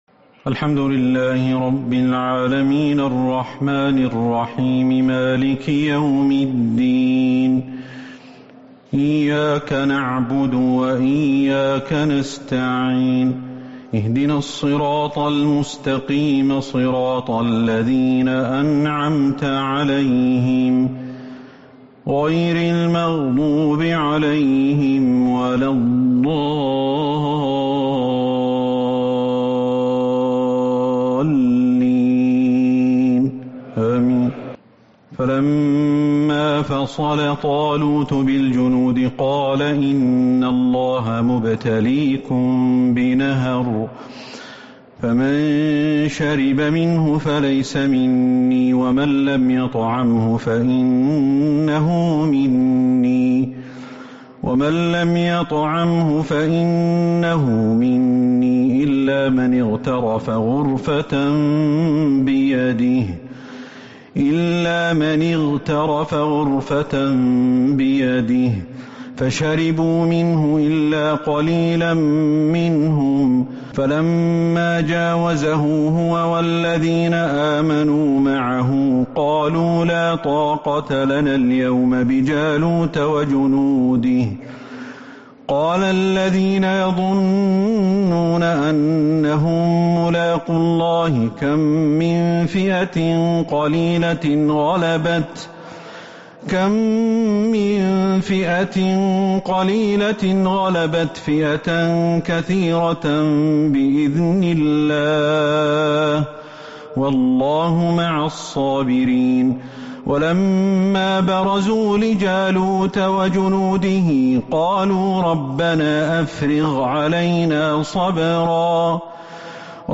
تراويح ليلة 3 رمضان 1443 من سورة البقرة {249- 274} Taraweeh 3st night Ramadan 1443H > تراويح الحرم النبوي عام 1443 🕌 > التراويح - تلاوات الحرمين